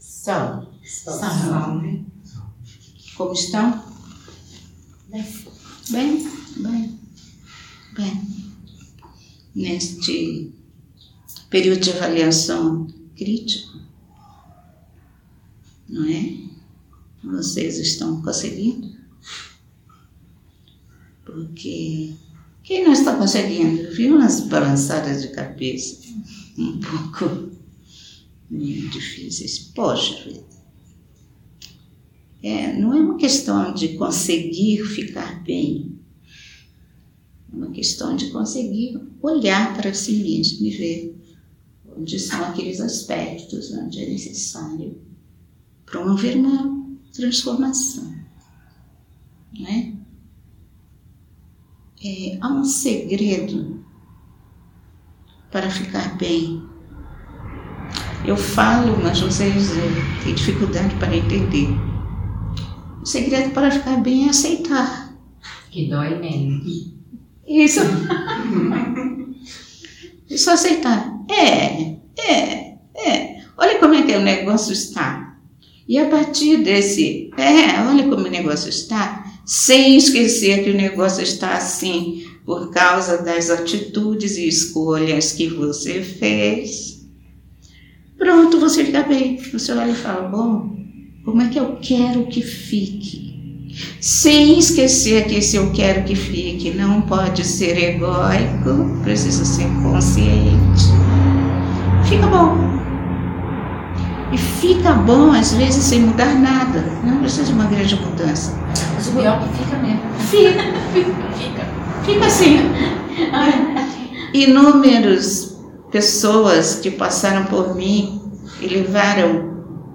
Palestra Canalizada